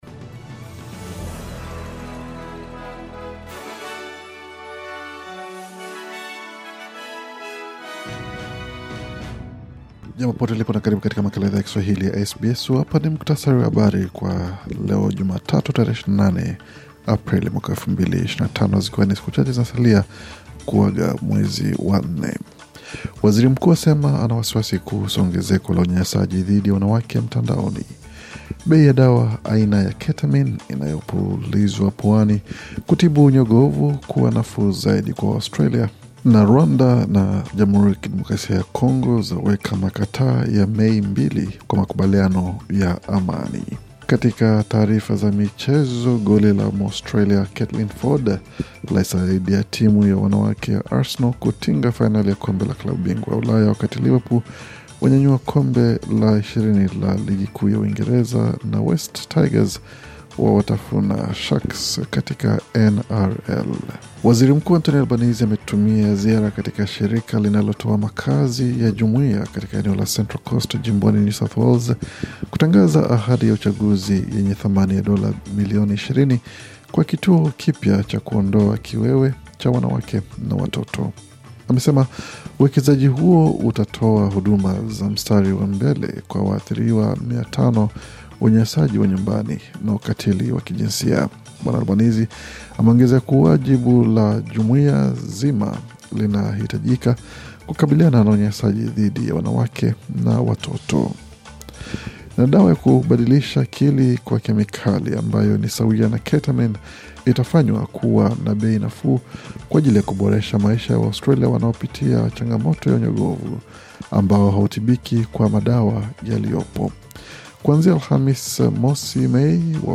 Taarifa ya Habari 28 Aprili 2025